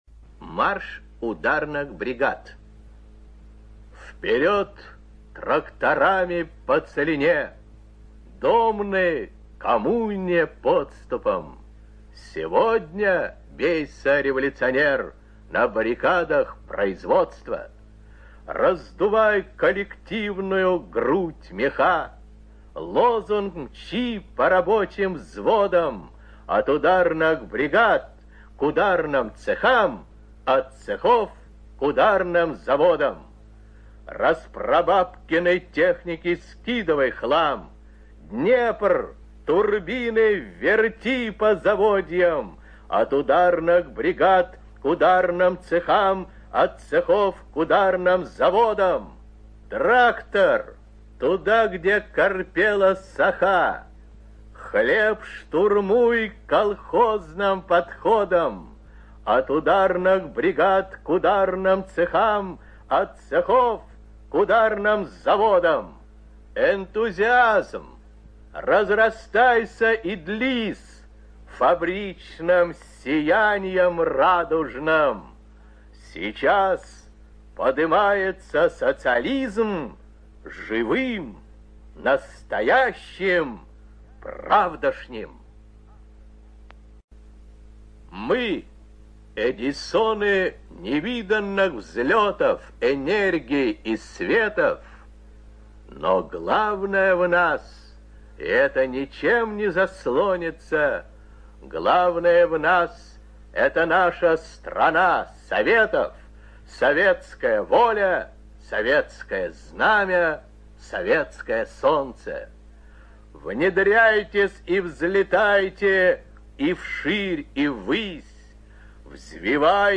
ЧитаетЛевитан Ю.
ЖанрПоэзия
Маяковский В - Два стихотворения (Левитан Ю.)(preview).mp3